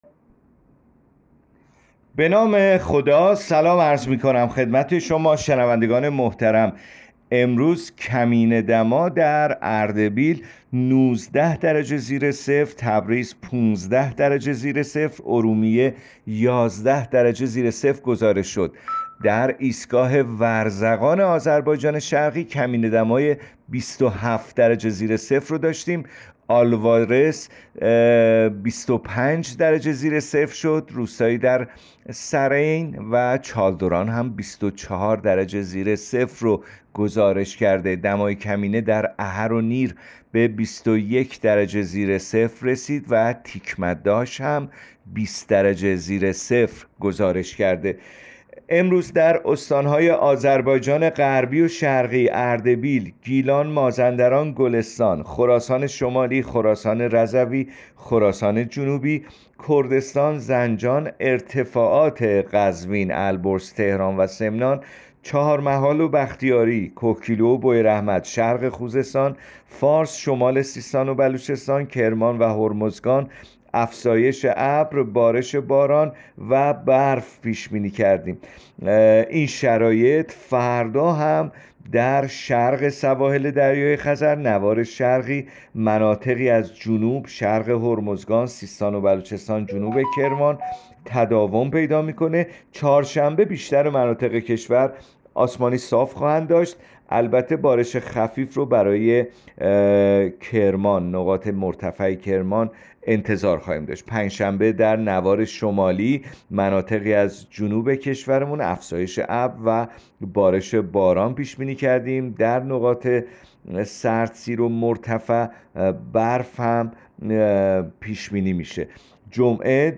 گزارش رادیو اینترنتی پایگاه‌ خبری از آخرین وضعیت آب‌وهوای ۶ اسفند؛